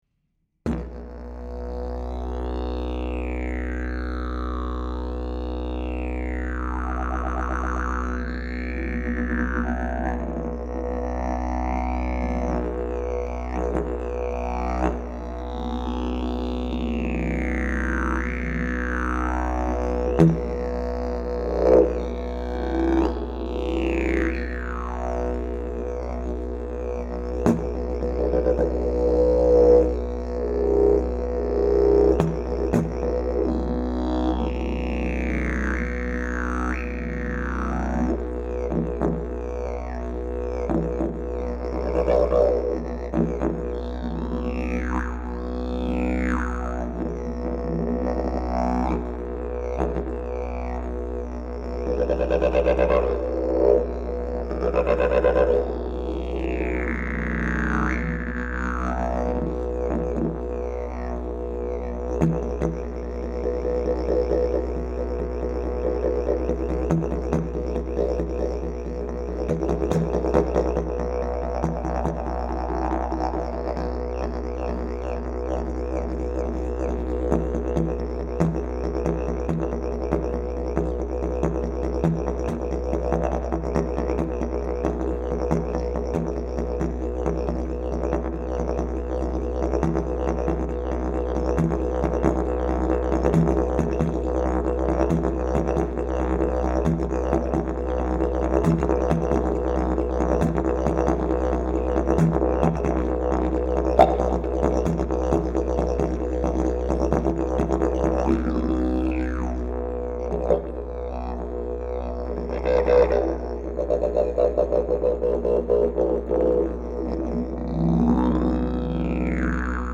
Key: B Length: 73" Bell: 7.5" Mouthpiece: Canary Back pressure: Very strong Weight: 5.6 lbs Skill level: Any
Didgeridoo #670 Key: B